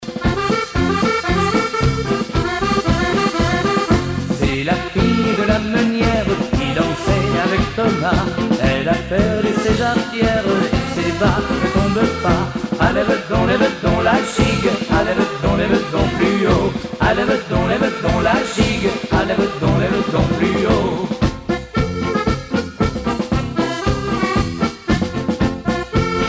Chants brefs - A danser
Pièce musicale éditée